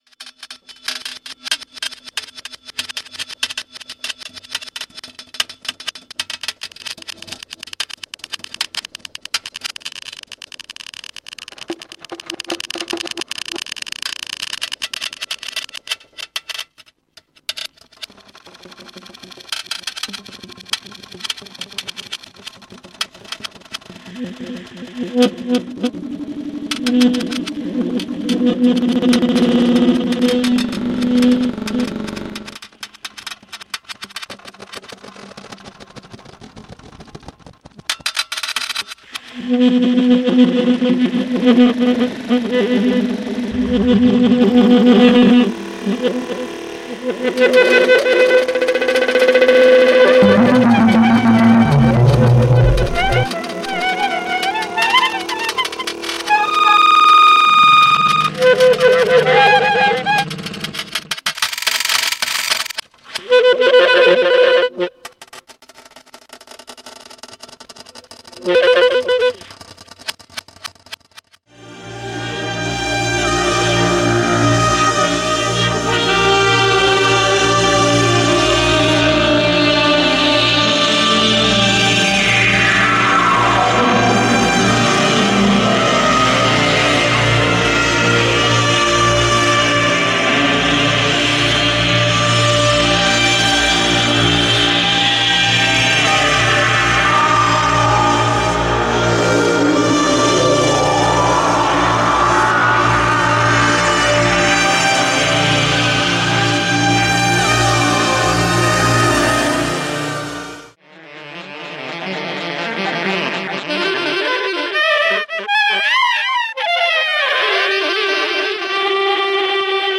synthetic times not possible in the acoustic world.